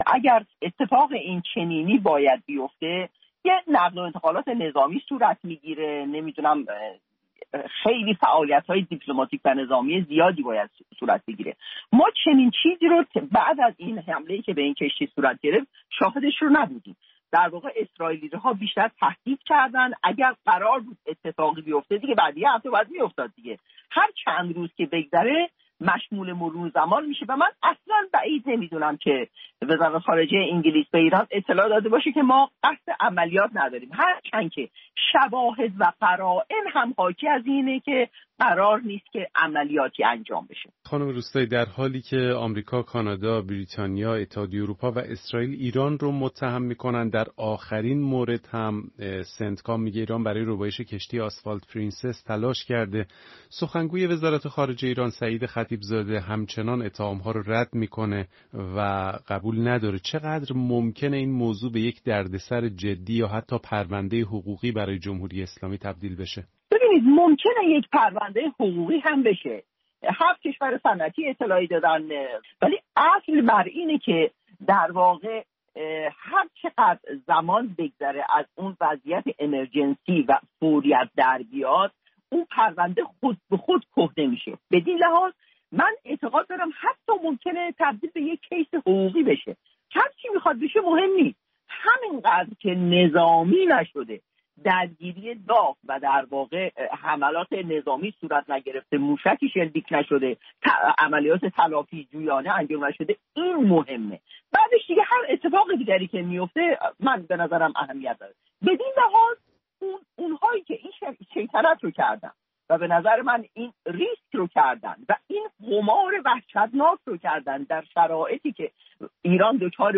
در گفت‌وگویی